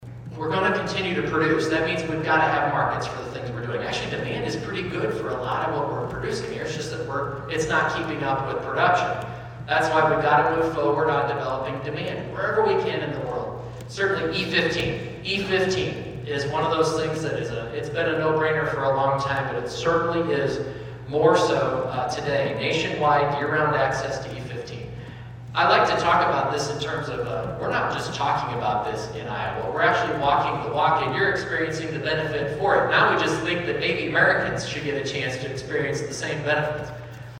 Iowa Secretary of Agriculture was in the listening area earlier this week and served as the keynote speaker for the Carroll County Farm Bureau’s annual meeting and member appreciation dinner.